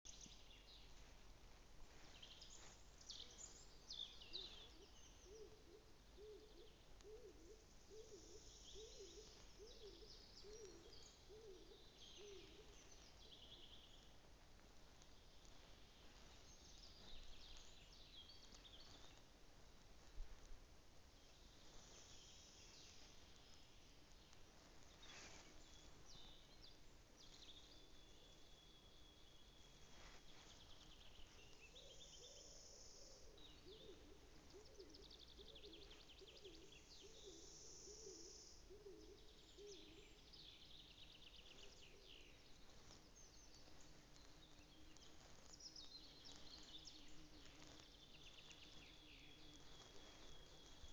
клинтух, Columba oenas
ПримечанияIeraksta pirmās 18 sekundes.. tuvumā ir arī lauku baloži.